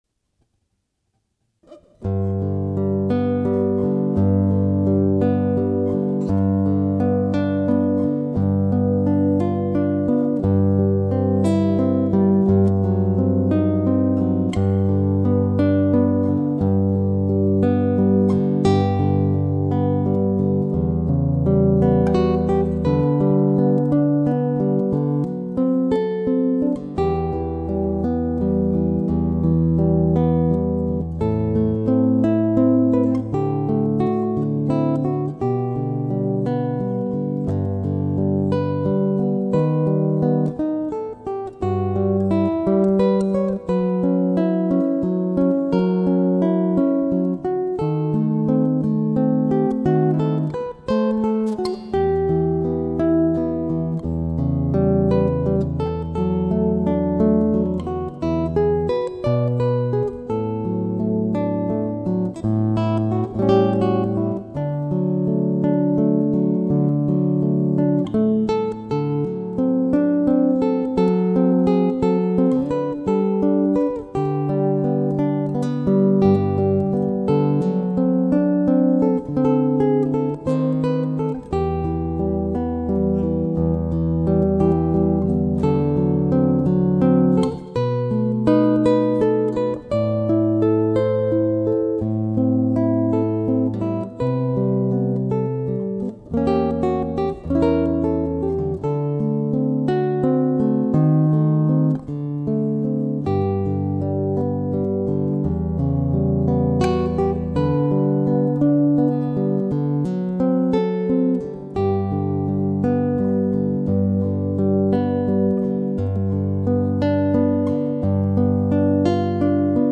classical guitarist